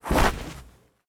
Foley Sports / Soccer
Scuffed Shot B.wav